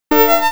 IKONKA GŁOśNIKA Przykład syntezy dźwięku skrzypiec opartego na 4 operatorach
4_oper_skrzypce.mp3